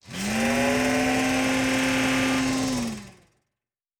pgs/Assets/Audio/Sci-Fi Sounds/Mechanical/Servo Big 10_1.wav at master
Servo Big 10_1.wav